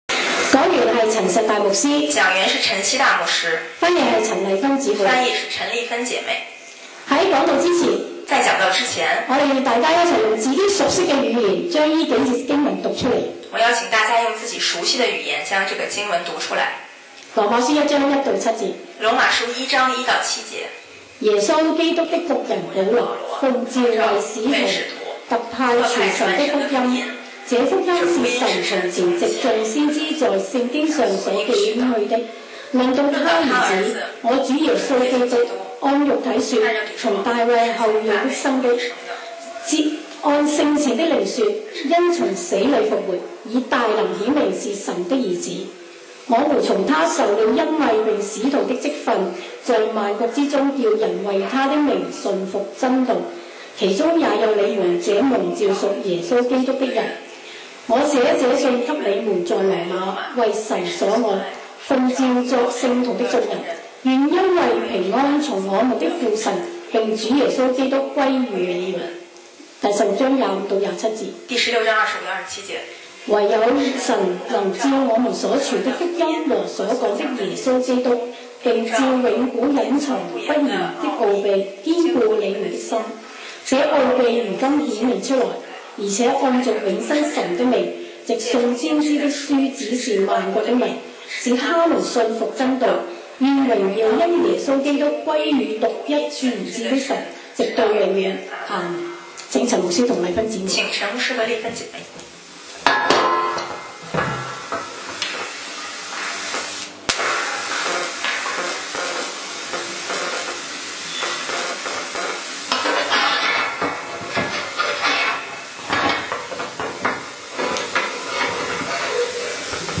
講道 Sermon 題目 Topic：爱的召唤 經文 Verses：罗1：1-7，16：25-27. 1耶稣基督的仆人保罗，奉召为使徒，特派传神的福音。